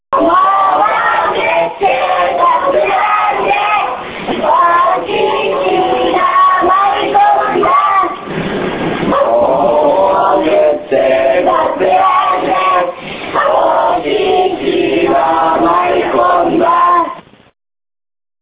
「モモクッテ　コテェラレネ　お獅子が舞い込んだ」子供たちの大声が22日早朝より椎津地区内に響き渡る。